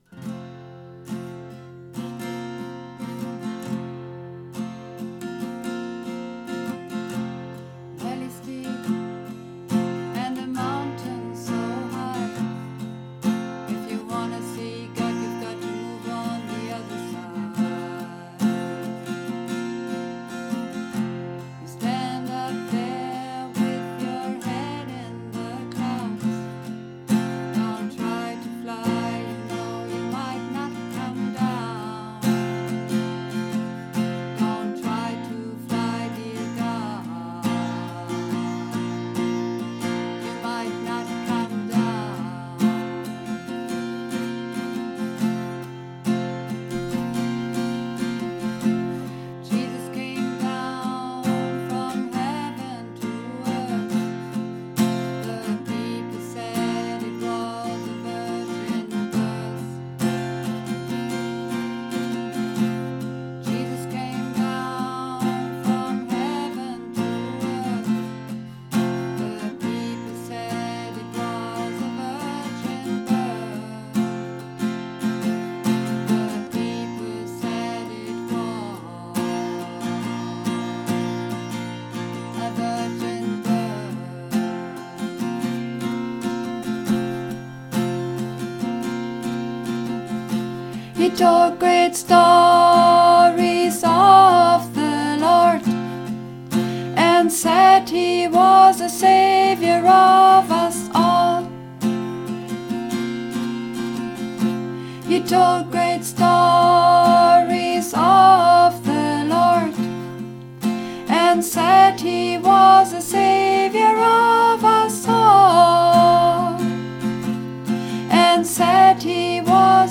Übungsaufnahmen - Hymn
Hymn (Sopran 1 - tiefer)
Hymn__3_Sopran_1_tiefer.mp3